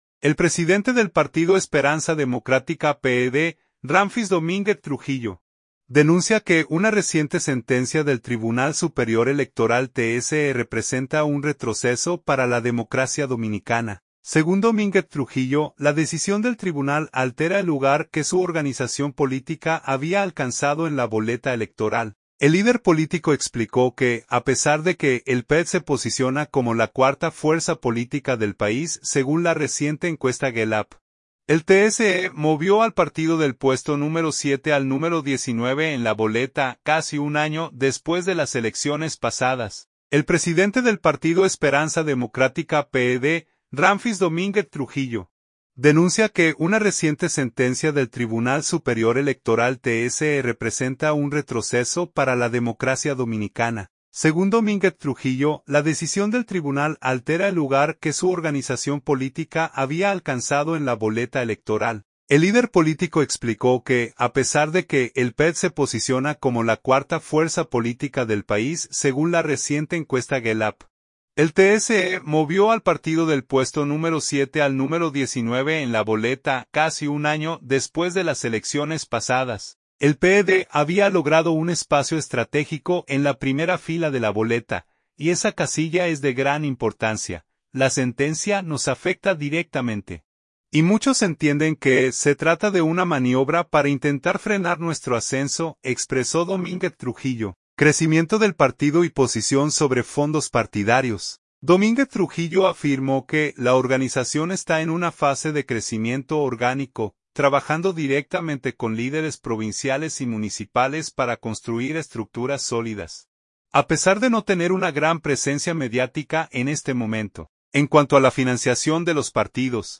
Ramfis Domínguez Trujillo hizo estas declaraciones durante su participación en el programa «El Matinal», transmitido por el Canal 5 del Grupo Telemicro.